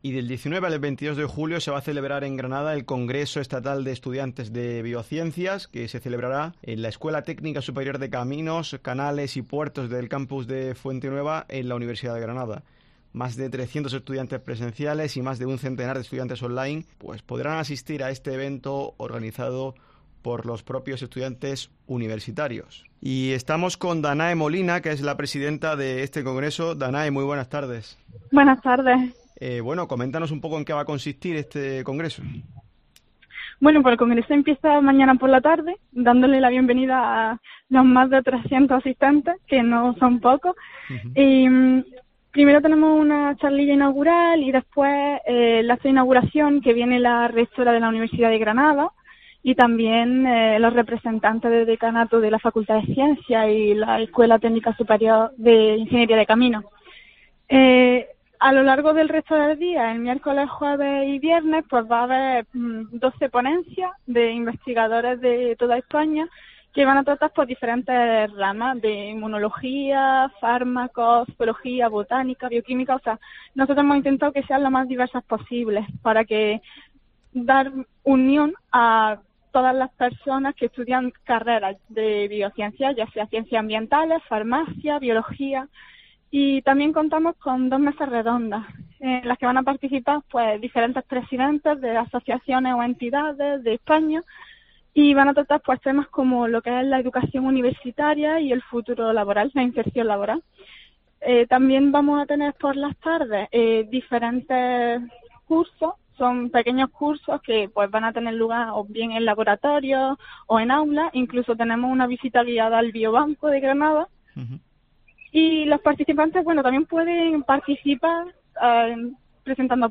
COPE GRANADA